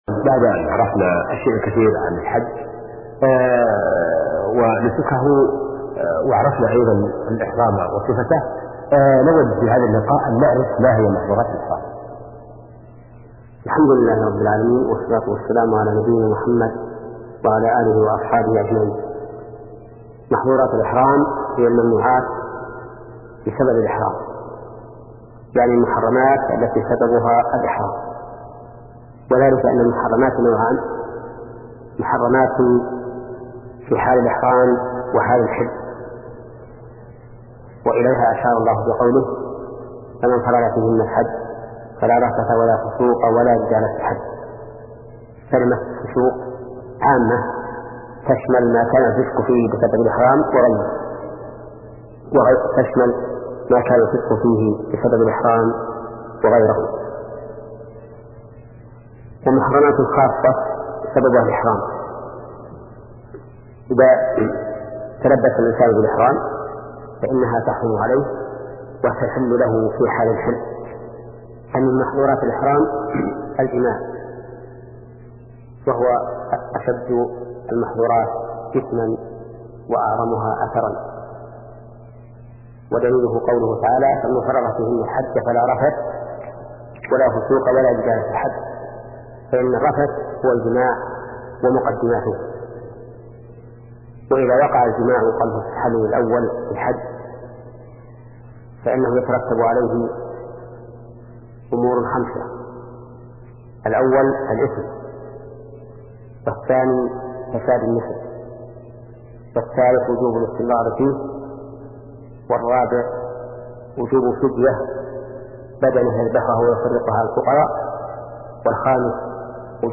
فقه العبادات [43] - للشيخ : ( محمد بن صالح العثيمين ) هناك محظورات في الحج يجب اجتنابها كالجماع ولبس المخيط والطيب.